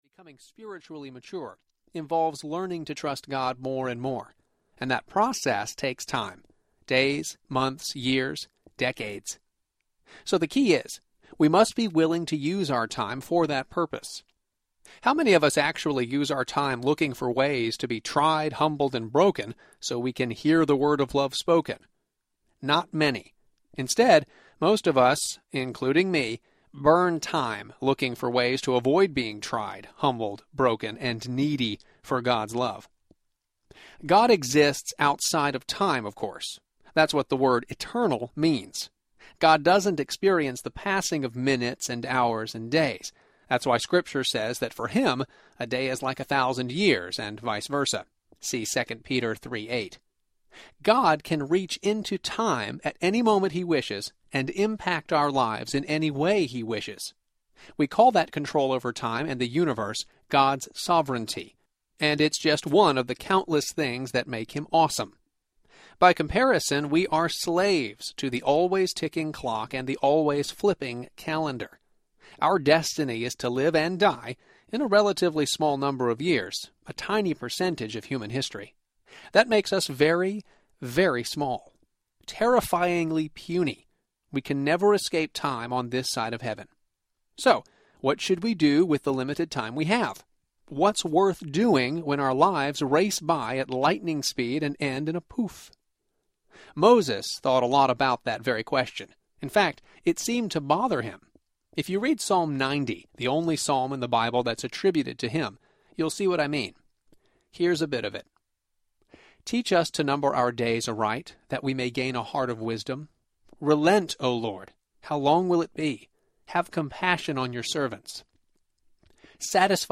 Wisdom on Time & Money Audiobook
Narrator
1.6 Hrs. – Unabridged